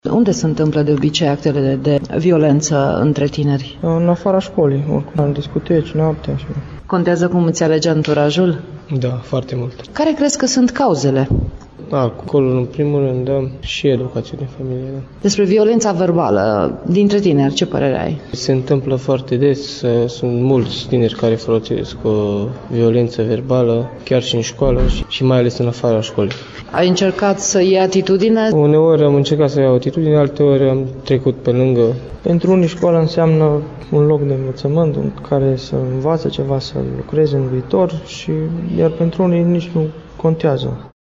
Simpozionul cu tema “ Delincvenţa juvenilă – o problemă a societăţii contemporane”  s-a desfăşurat astăzi la Colegiul Tehnic Reşiţa.